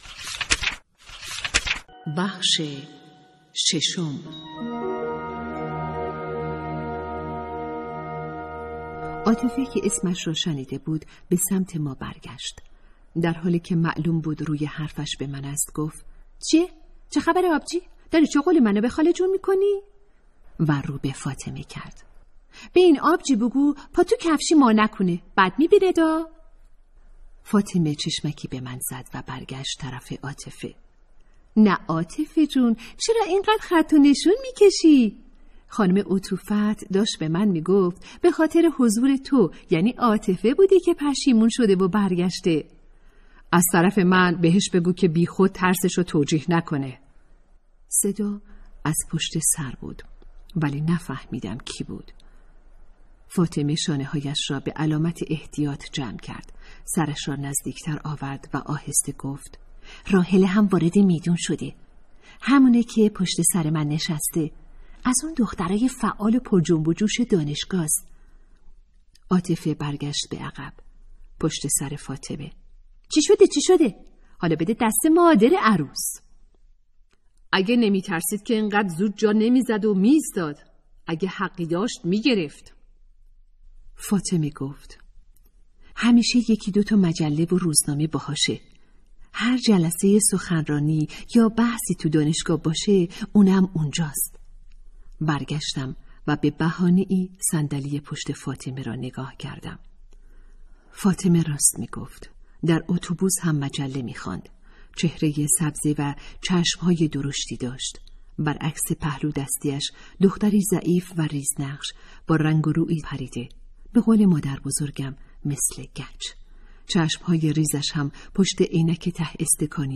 کتاب صوتی | دختران آفتاب (06)